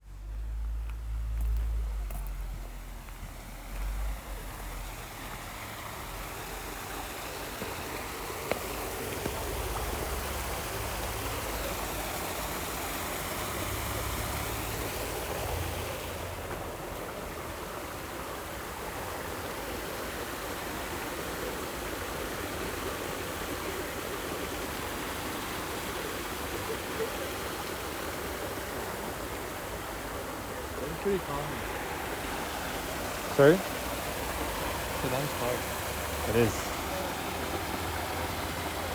nitobe-big-water.m4a